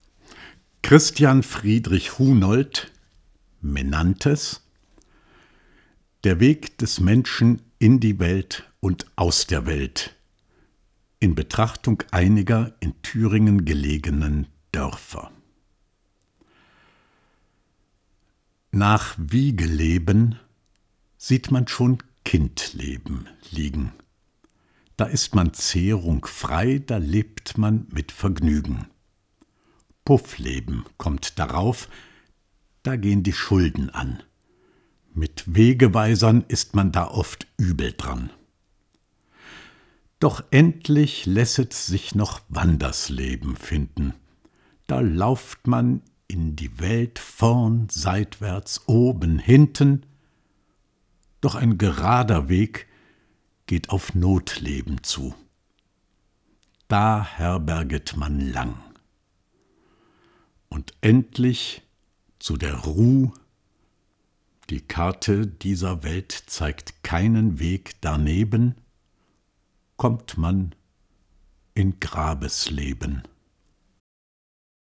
Jede Woche ein Gedicht